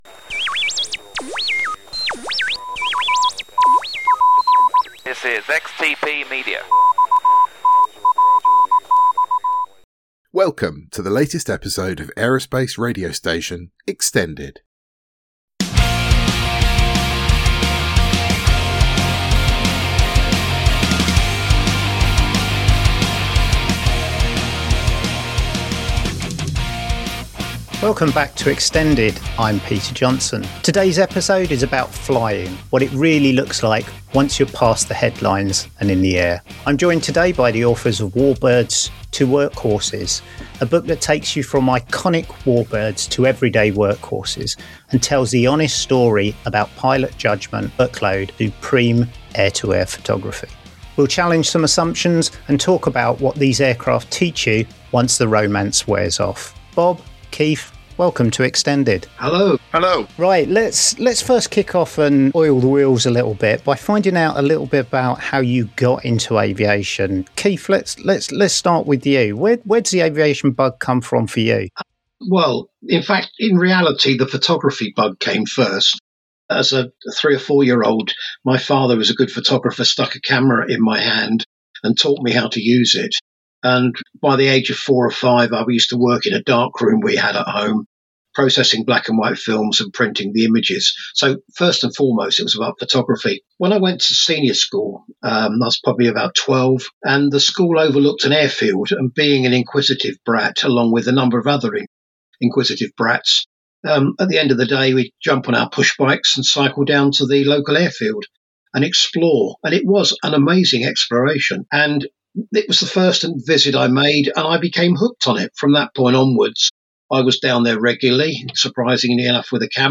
Together, they explore aviation beyond the headlines, following the journey of the book from iconic warbirds to the quieter, often overlooked aircraft that shape everyday flying. The conversation looks at judgement, workload, and the human realities of operating and photographing aircraft across very different eras.